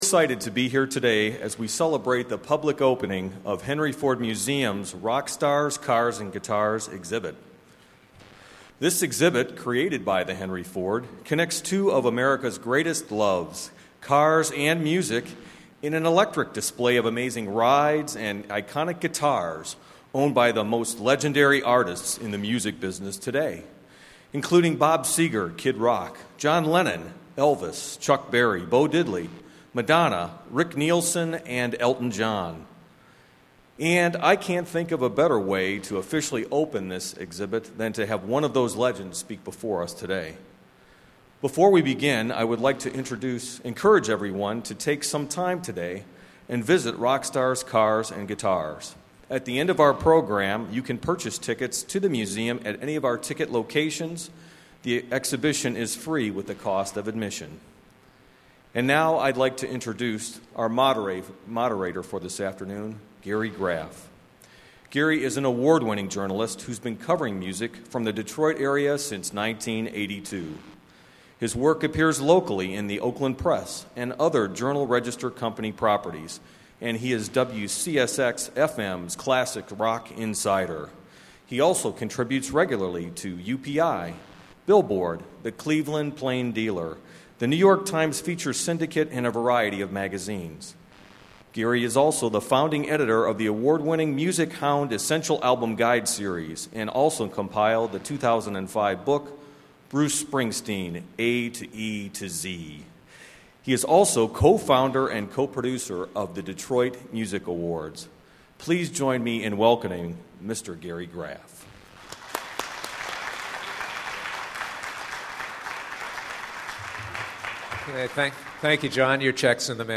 (Size: 1.7 MB) Don Was Lecture Series Downloadable version of the June 9, 2007 lecture by legendary rock producer Don Was.
Lecture Audio (Size: 28 MB)